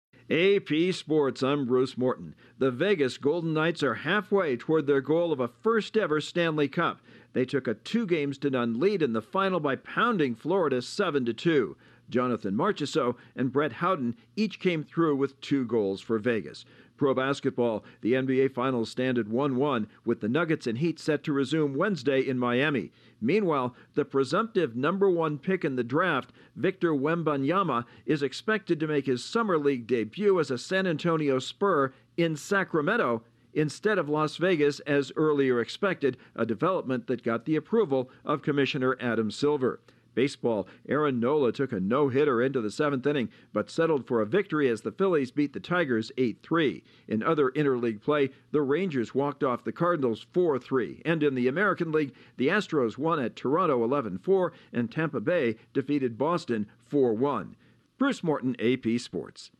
Vegas takes a giant step toward a Stanley Cup, we learn more about the much-anticipated debut of the presumptive top pick in the NBA draft and a Phillies pitcher has to settle for a win instead of a no-hitter. Correspondent